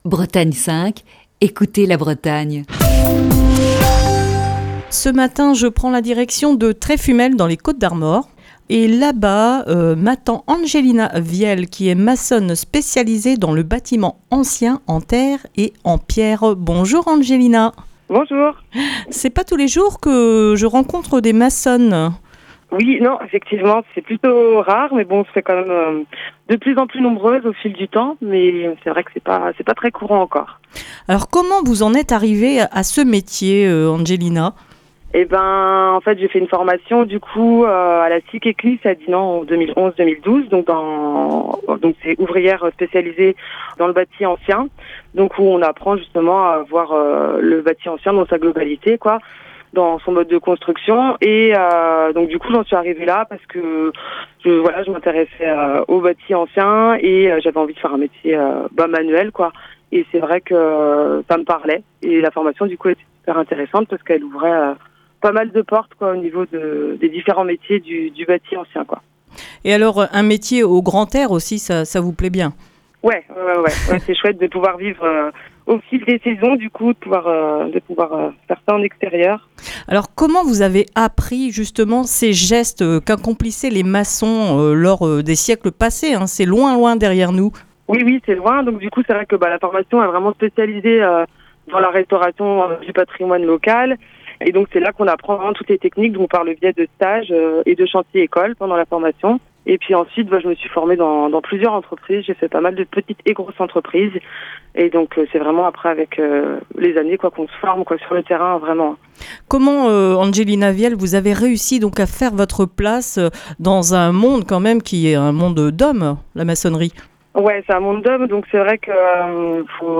Ce vendredi dans le coup de fil du matin